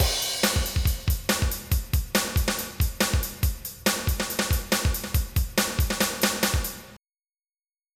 MIDI Music File